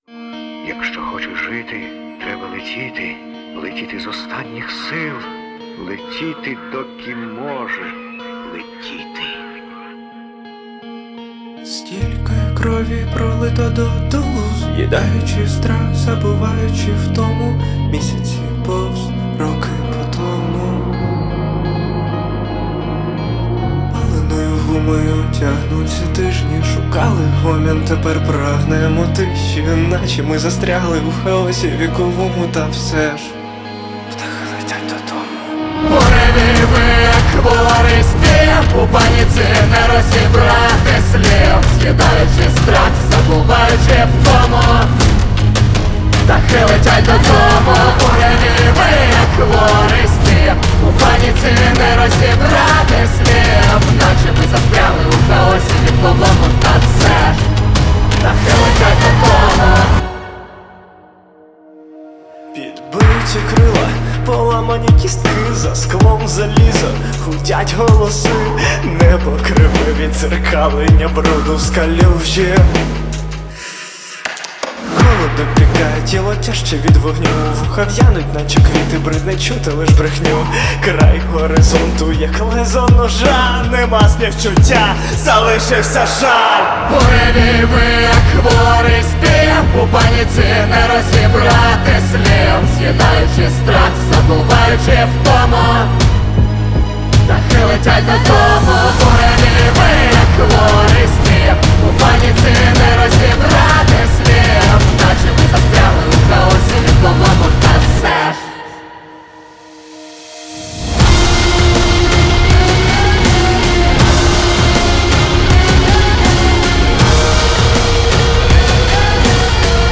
• Жанр: Alternative, Indie